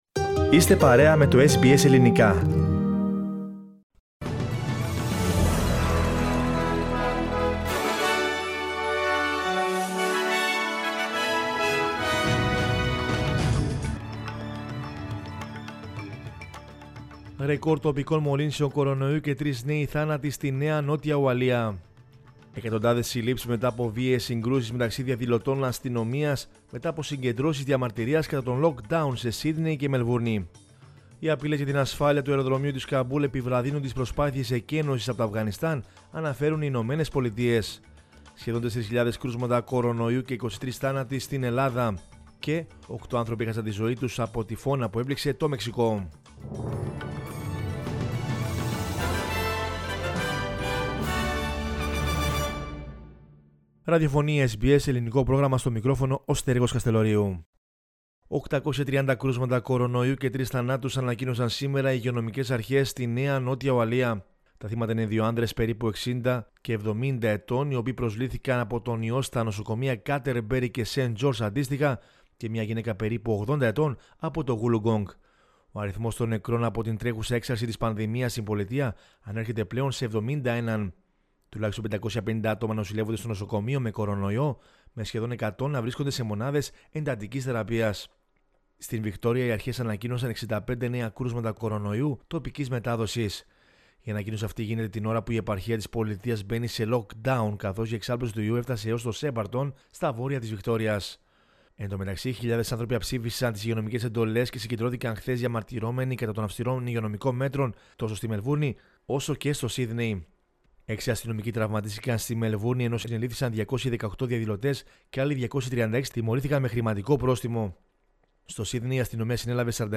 News in Greek from Australia, Greece, Cyprus and the world is the news bulletin of Sunday 22 August 2021.